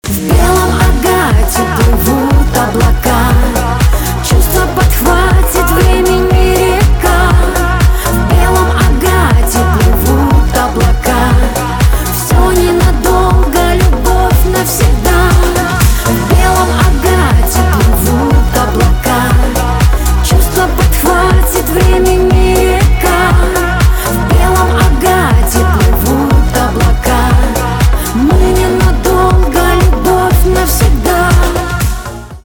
шансон
чувственные